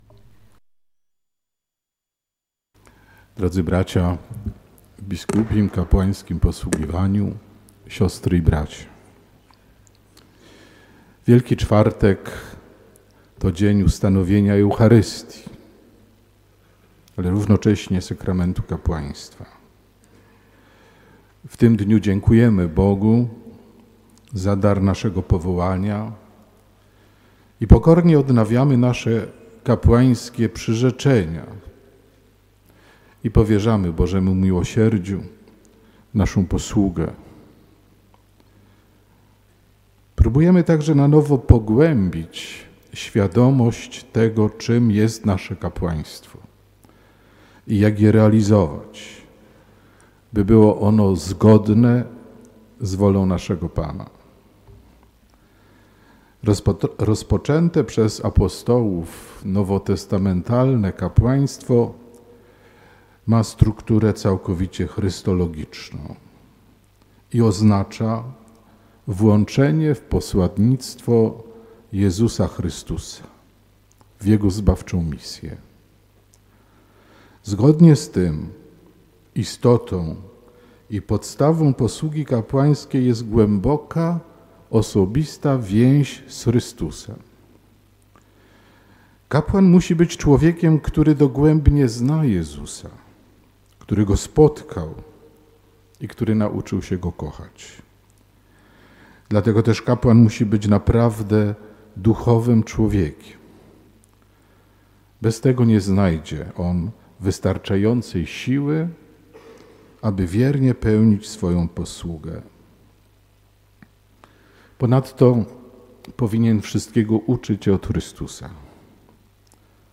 Msza Krzyżma [posłuchaj homilii]
W Katedrze zakończyła się Msza Krzyżma, której przewodniczył Arcybiskup Józef Kupny, metropolita wrocławski.
Msza Krzyżma w Katedrze Wrocławskiej pod przewodnictwem JE Abp Józefa Kupnego
msza-kazanie-krzyzma.mp3